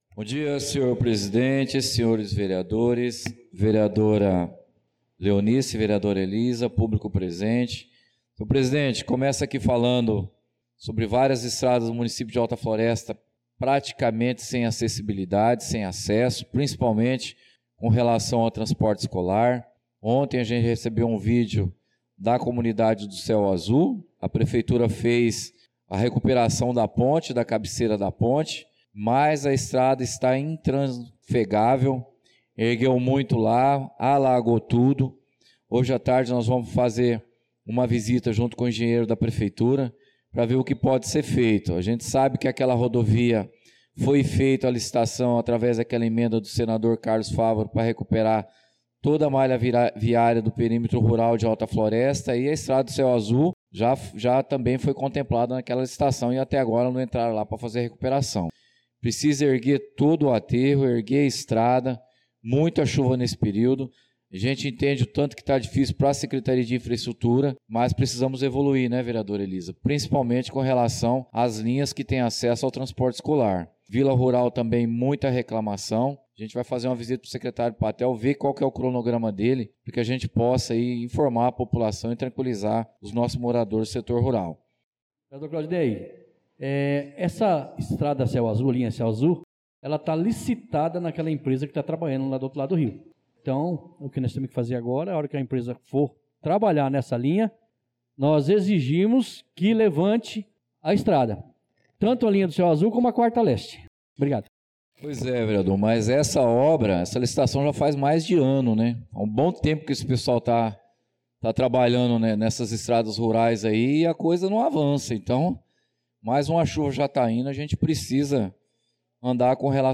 Pronunciamento do vereador Claudinei de Jesus na Sessão Ordinária do dia 06/03/2025